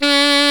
Index of /90_sSampleCDs/Roland L-CDX-03 Disk 1/SAX_Alto Short/SAX_Pop Alto
SAX C 3 S.wav